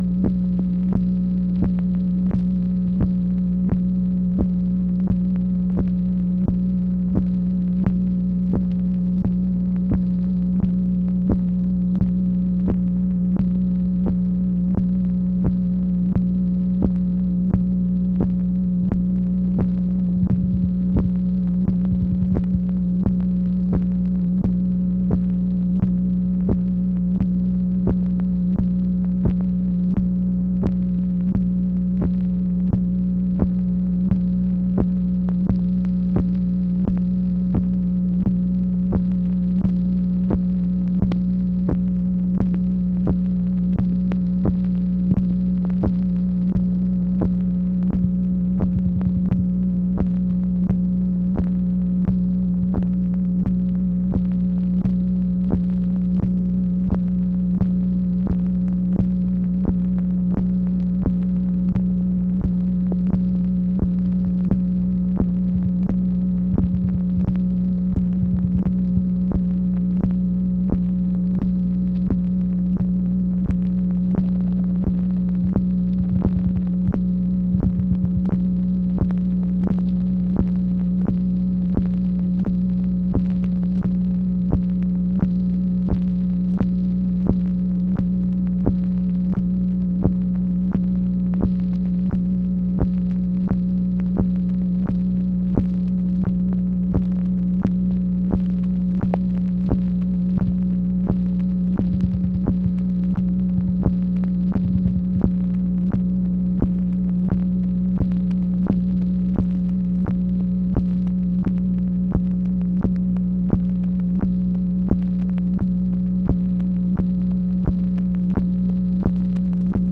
MACHINE NOISE, June 13, 1966
Secret White House Tapes | Lyndon B. Johnson Presidency